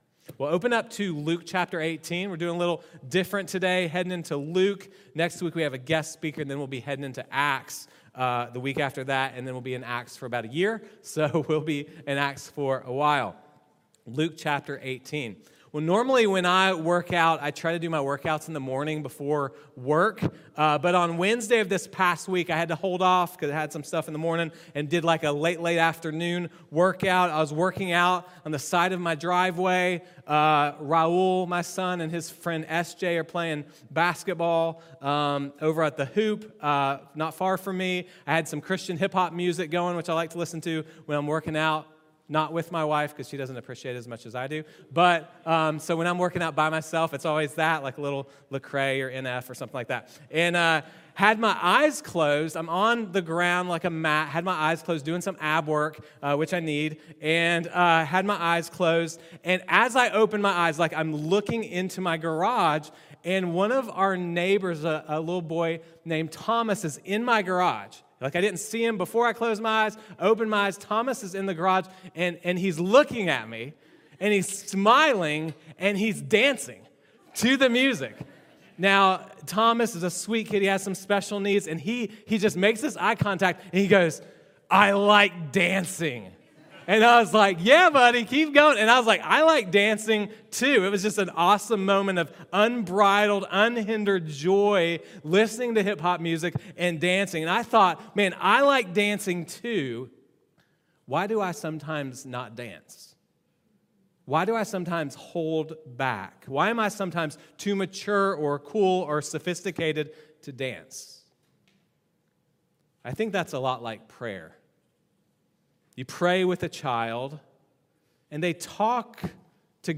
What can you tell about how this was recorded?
These sermons have been preached outside a normal sermon series at Risen Hope.